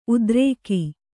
♪ udrēki